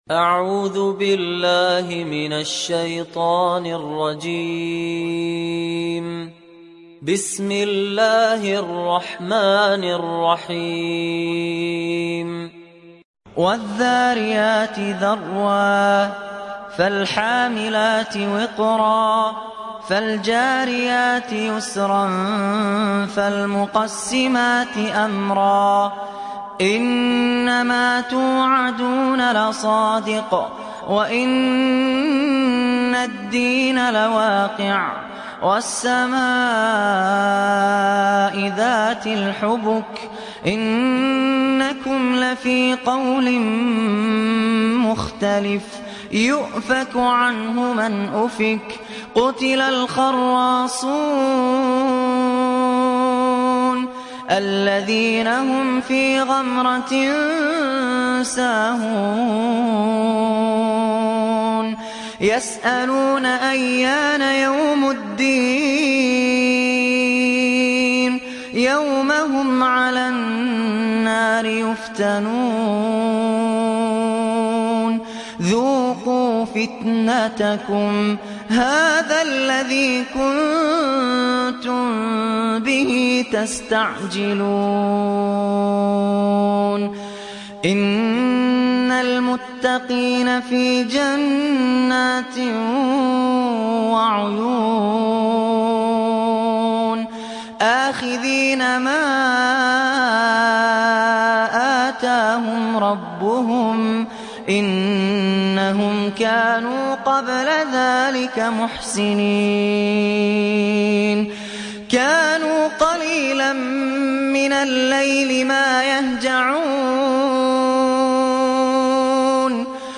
تحميل سورة الذاريات mp3 بصوت فهد الكندري برواية حفص عن عاصم, تحميل استماع القرآن الكريم على الجوال mp3 كاملا بروابط مباشرة وسريعة